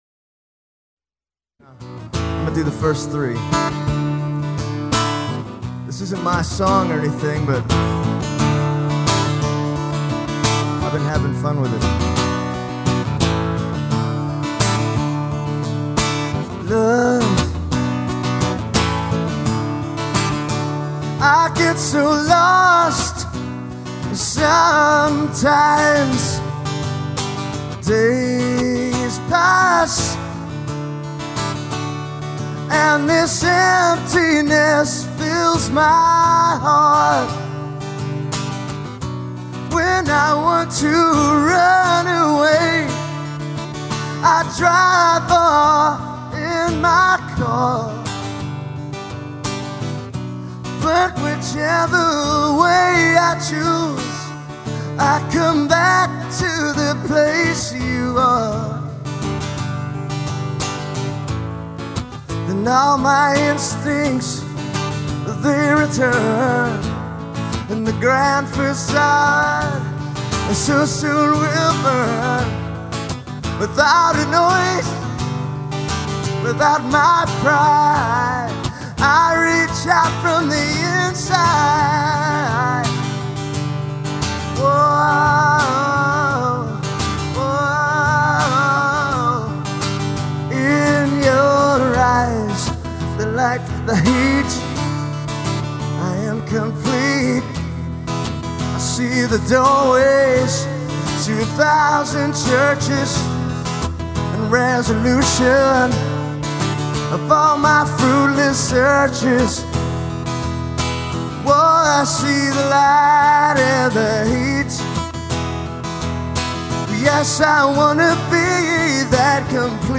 bold cover version
which this live acoustic performance on my Dallas